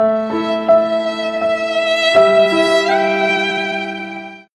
Sad Violin (the Meme One)